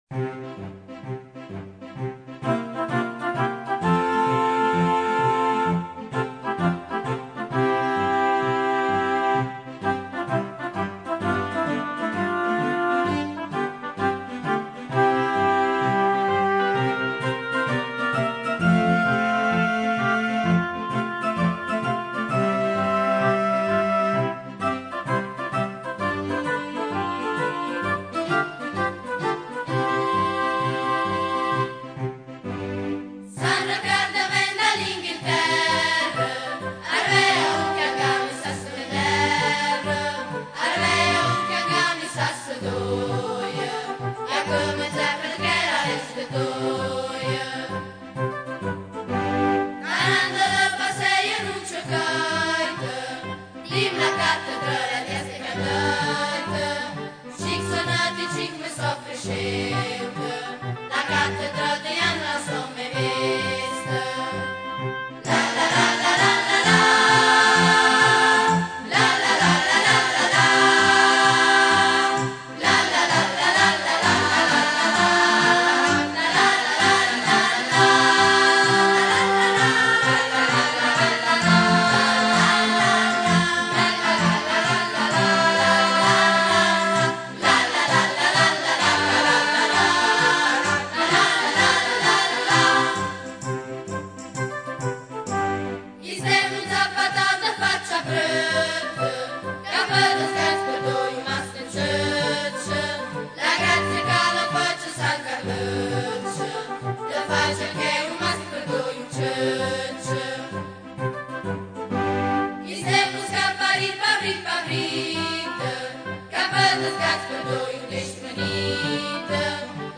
Canti popolari: Canzone a San Riccardo
Il coro della Scuola Secondaria di 1° grado "A. Manzoni" tratto dal disco "Strada facendo ..."
canto in dialetto andriese